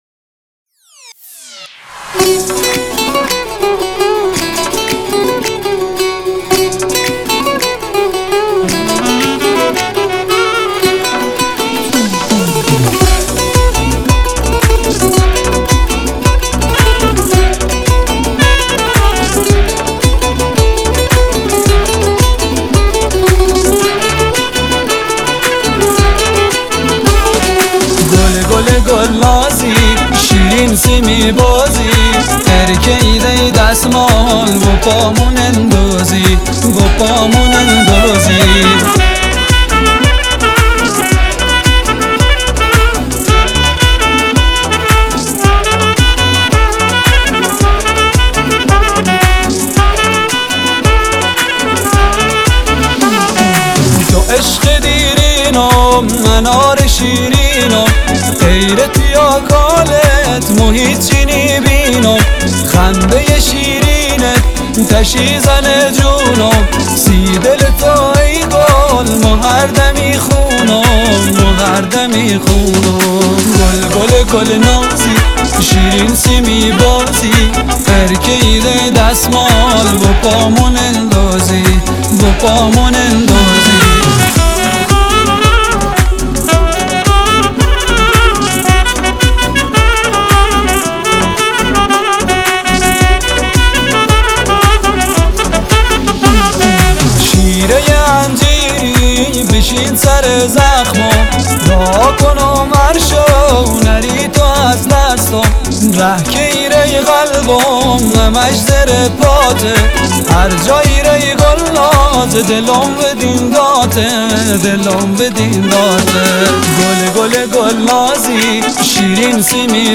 آهنگ بختیاری